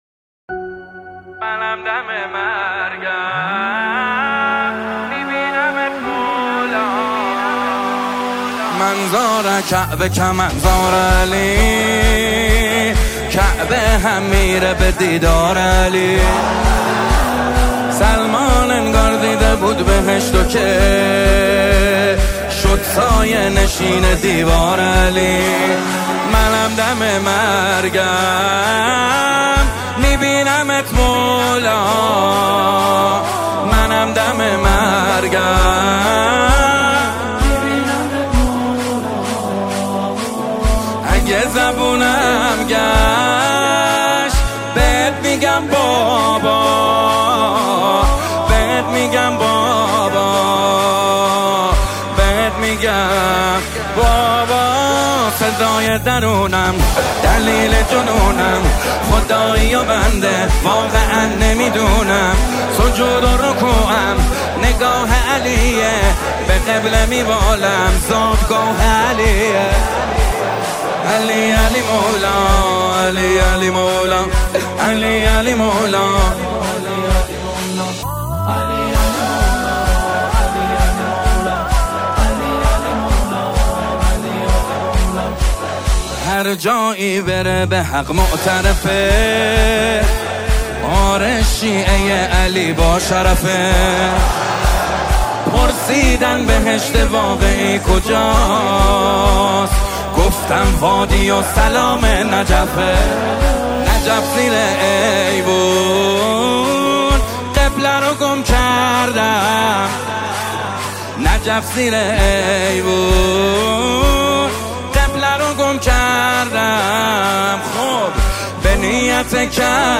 مداحی استودیویی